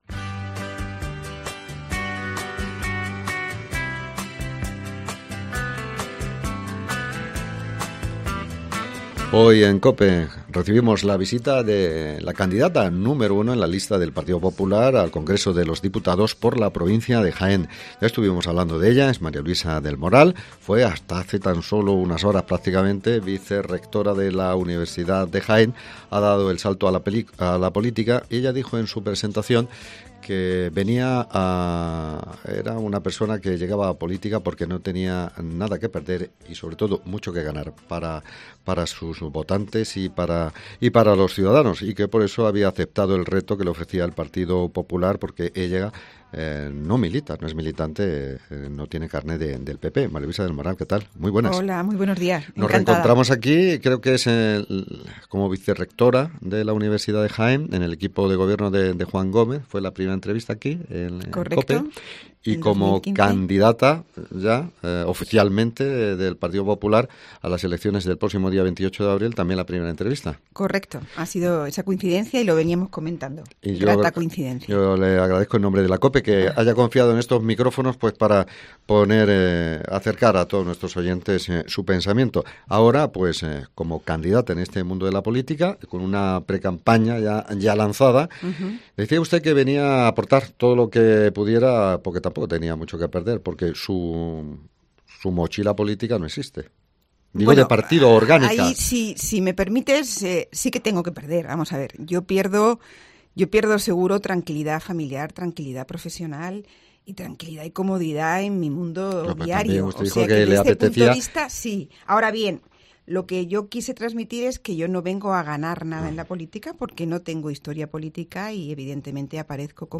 En su primera entrevista en radio, la candidata del PP, ha ido desgranando algunas de las ideas y pretensiones que quiere llevar al Congreso de los Diputados.